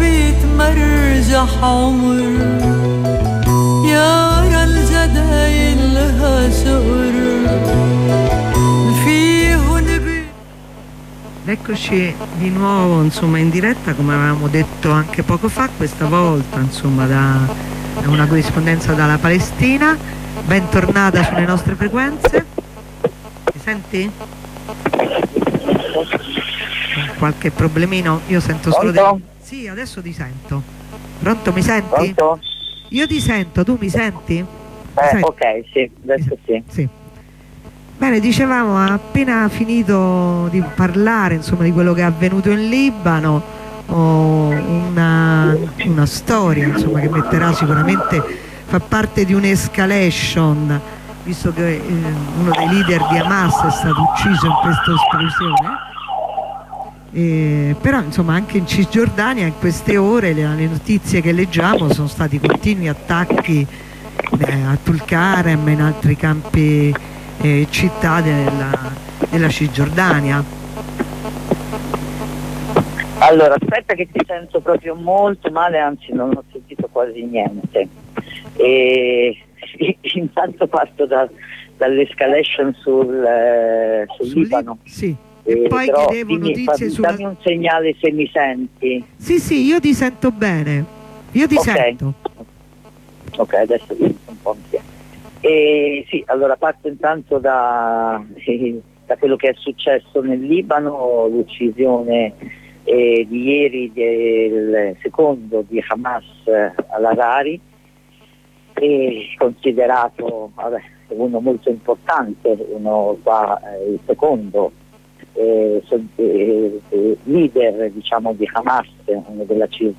Una compagna dalla Palestina ci parla dello sciopero generale per l'assasinio di Saleh al-Arouri, alto dirigente di Hamas, avvenuto ieri martedi 2 gennaio 2024, con un attacco dell’esercito israeliano, effettuato con un drone nell’area meridionale nella città di Beirut; ci racconta dei continui attacchi in Cisgiordania, delle gravissime condizioni della popolazione palestinese a Gaza sottoposta non solo a continui bombardamenti, ma anche senza più acqua e cibo e nessun luogo sicuro in cui rifugiarsi.